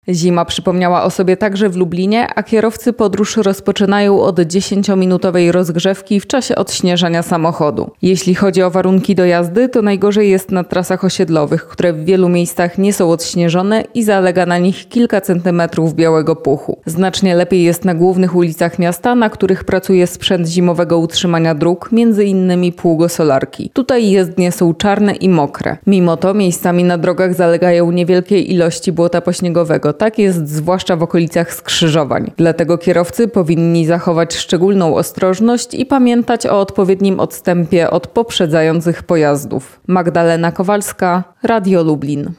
Zima w Lublinie - relacja z dróg